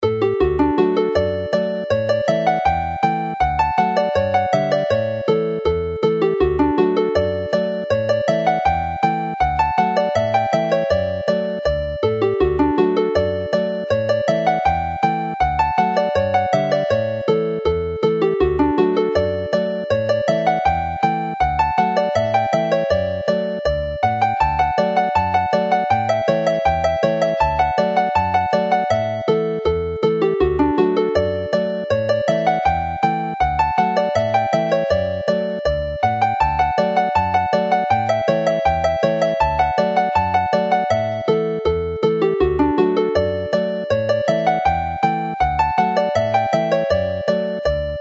is a suitably lively tune to finish the set.